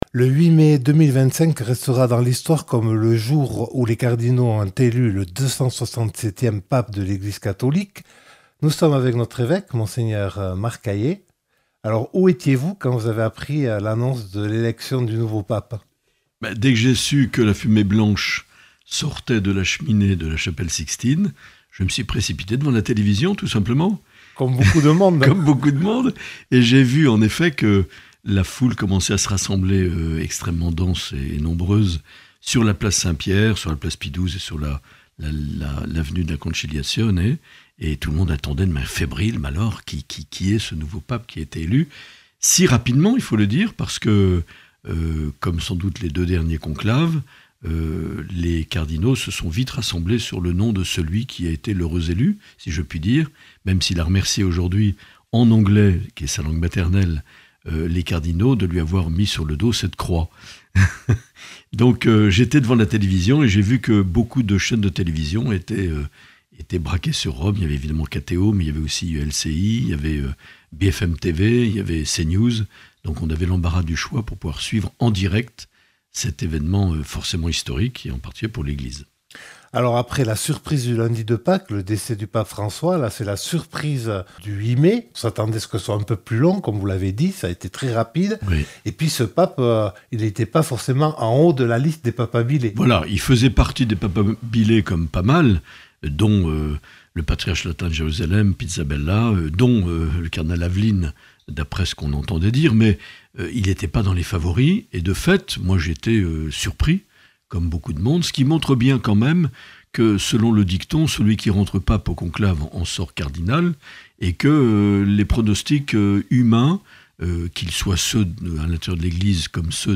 Un entretien enregistré le 9 mai 2025.